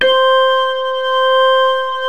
B3-ORGAN 1.wav